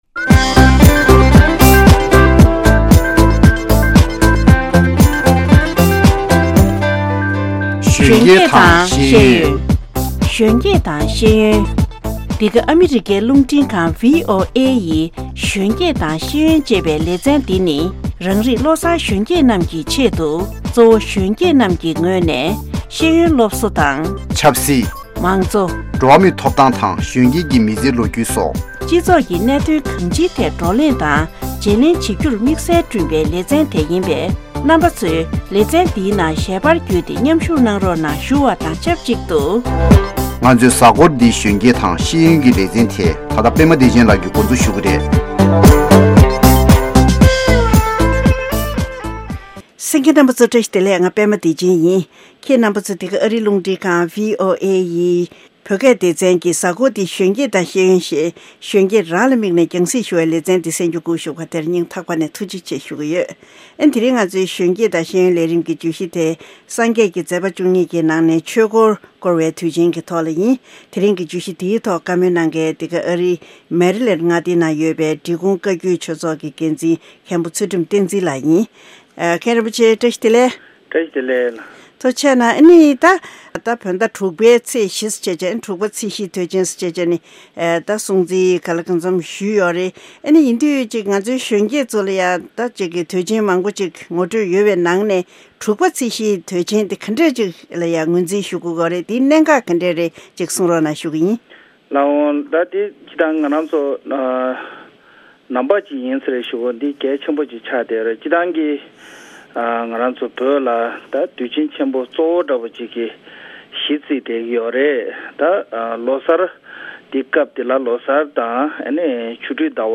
བཅའ་འདྲི་ཞུས་པ་ཞིག